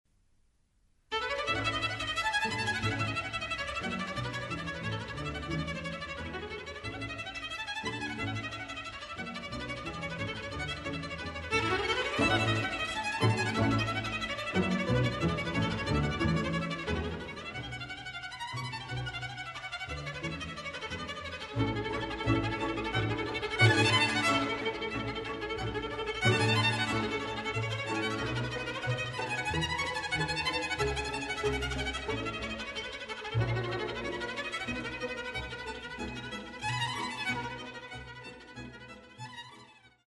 【所屬類別】 XRCD唱片　　古典音樂
Allegro vivace [3'11]<------試聽＊